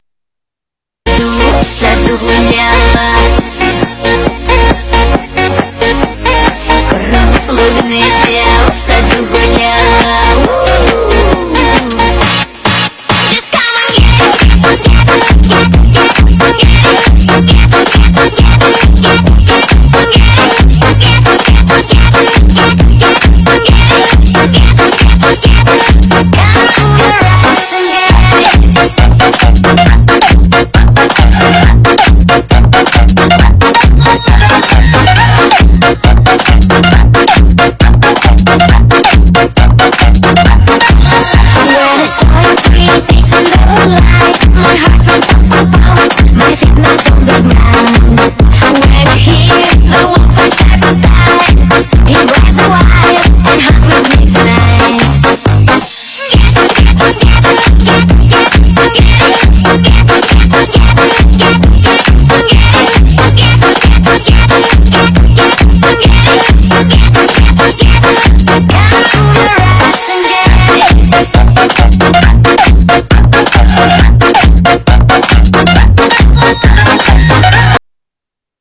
- Muzyka elektroniczna